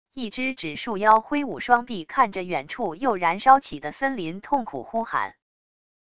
一只只树妖挥舞双臂看着远处又燃烧起的森林痛苦呼喊wav音频